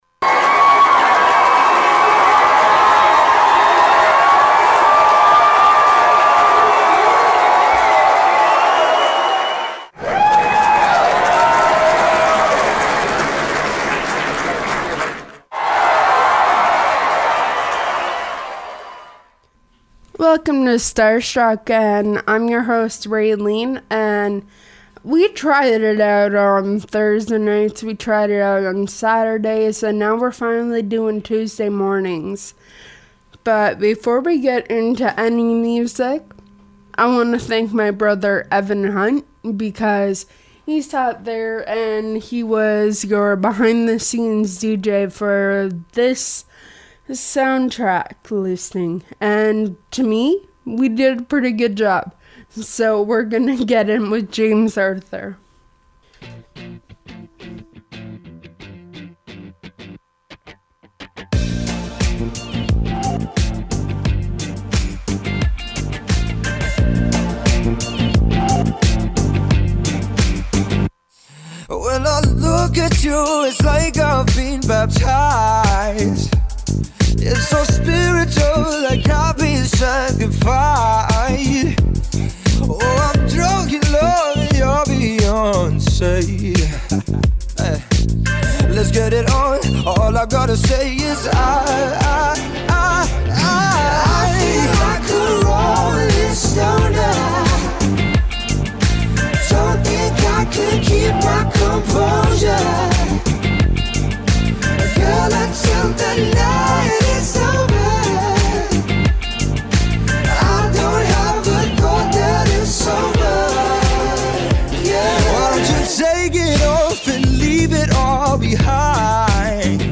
An Open Format Music Show.